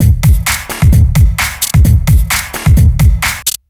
TSNRG2 Breakbeat 004.wav